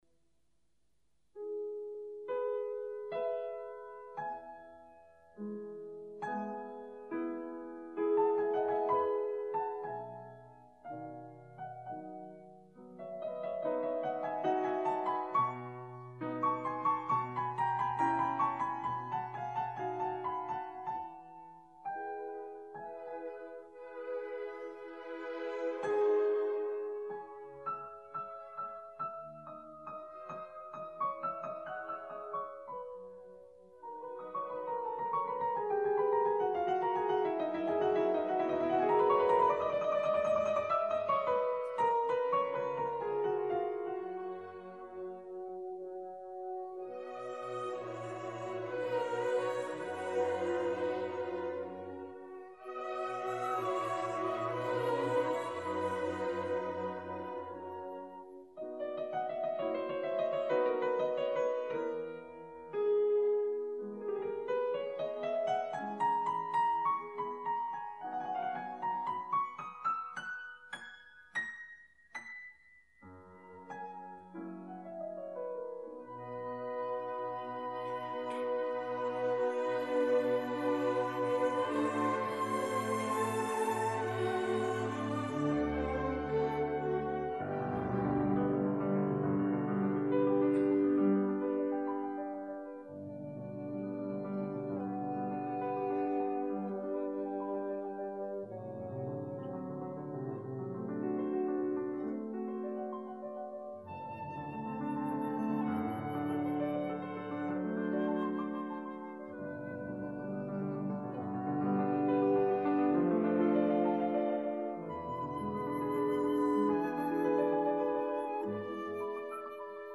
Piano
(Tracks 4-6, recorded: Jerusalem, 1/13/1970)